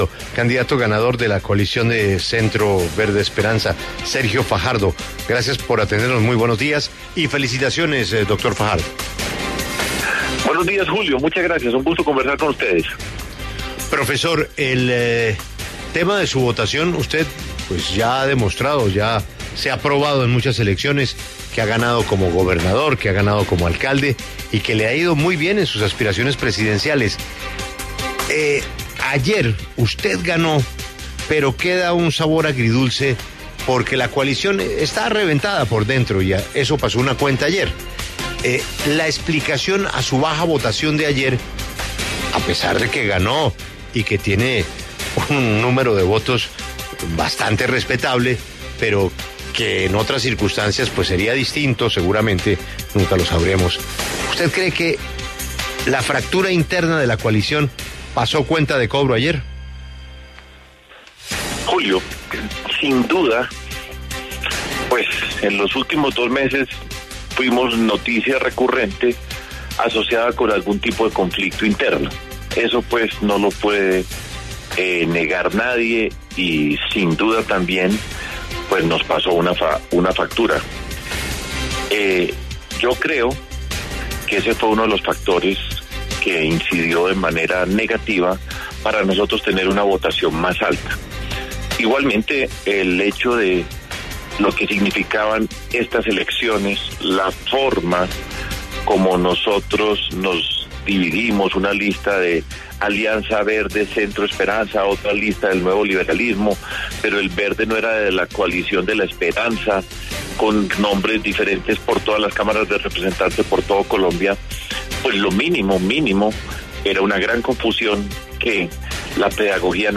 En diálogo con La W, el candidato presidencial Sergio Fajardo se pronunció sobre su triunfo en la consulta interpartidista de la coalición Centro Esperanza y sostuvo que este es el primer paso del camino que comenzará a recorrer hacia la Presidencia de la República.